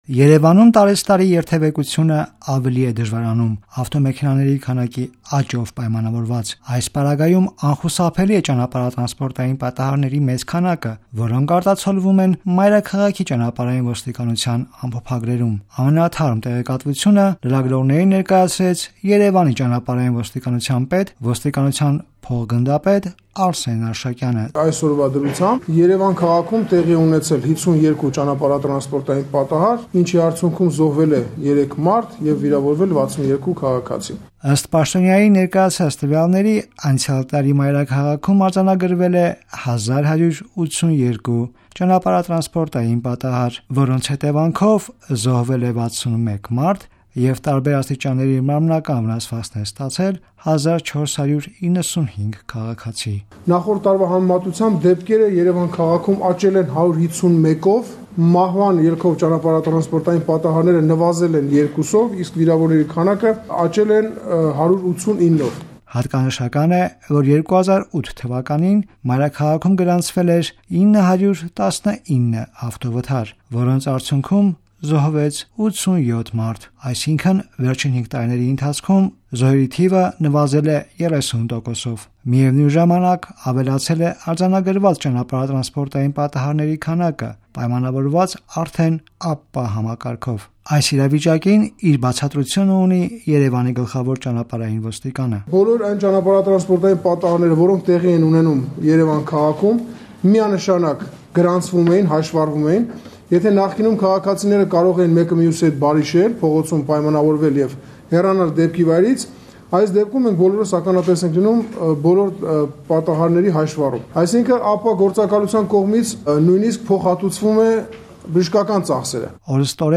Վերջին 5 տարիների ընթացքում մայրաքաղաքում ճանապարհատրանսպորտային պատահարների hետևանքով զոհերի քանակը նվազել է 30 տոկոսով, այսօր կայացած ասուլիսին հայտարարել է Երեւանի Ճանապարհային ոստիկանության  պետ, ոստիկանության փոխգնդապետ Արսեն Արշակյանը: Ըստ նրա, միևնույն ժամանակ  ԱՊՊԱ համակարգի ներդրմամբ ավելացել է ավտովթարների թիվը: